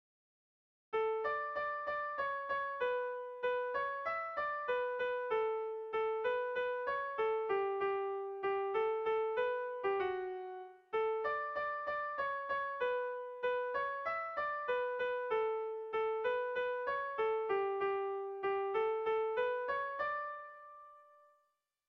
Irrizkoa
Zortziko txikia (hg) / Lau puntuko txikia (ip)
ABAB2